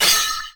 katana-clash2
Tags: sword